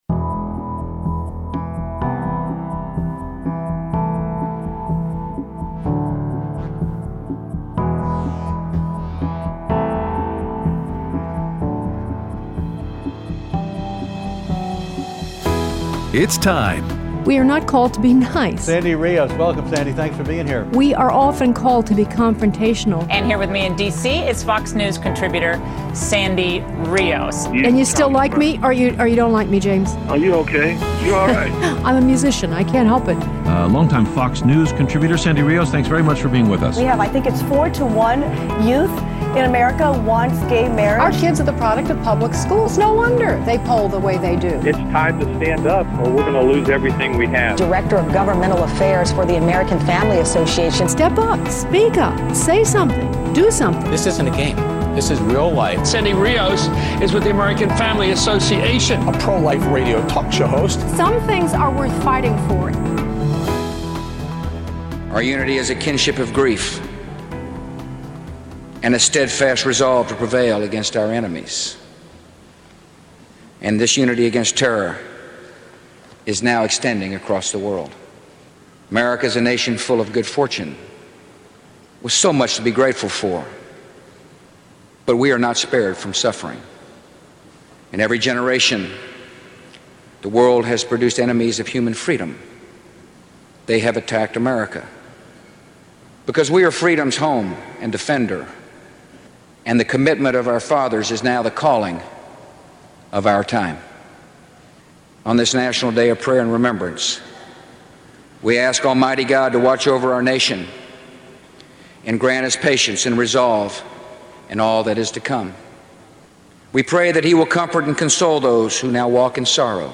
Live at the Values Voter Summit